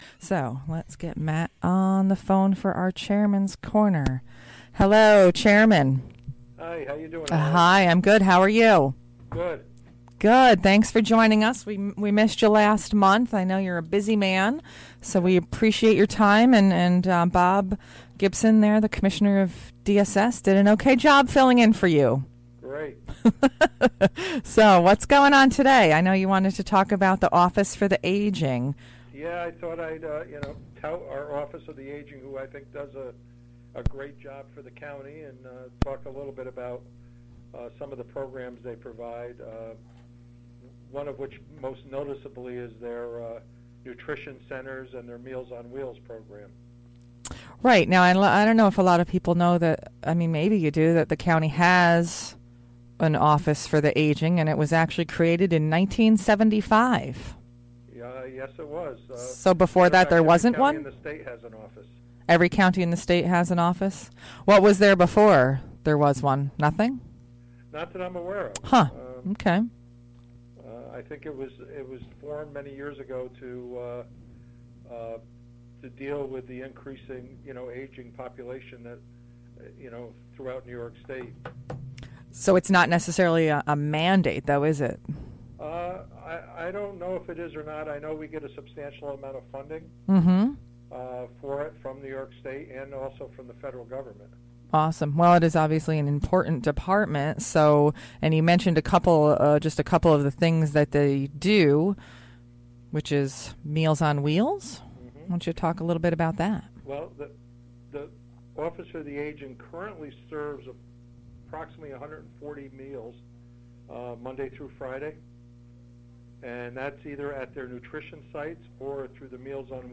Columbia County Board of Supervisors Chairman Matt Murrell gives his radio report about what's going on with the county.